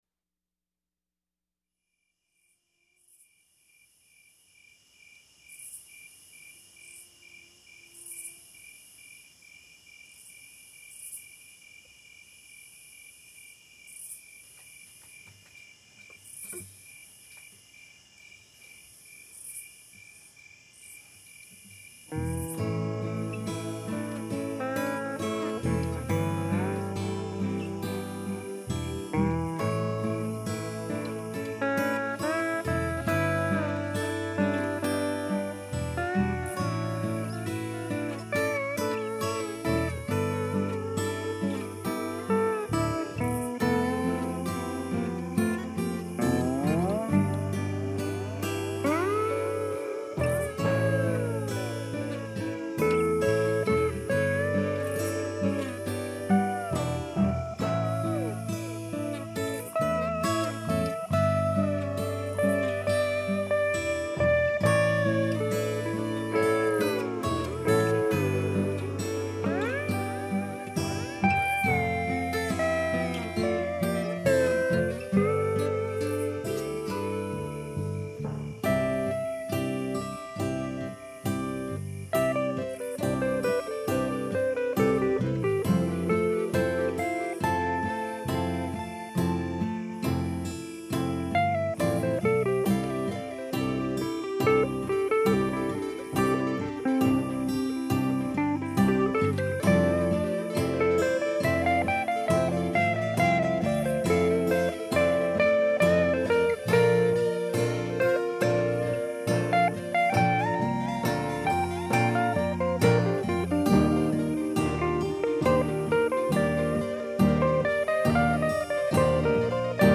hear's an Akai Headrush being overused.
Even though I can truthfully say I say down and simply started playing adding track after track with no actual 'writing' taking place .... it still headed into a direction that will have the same effect on you as sodium pentothal so you wouldn't be able to drive or operate heavy machinery for several days after.
Note: the very first note on the slide is way outta tune but it's fine after that so don't panic.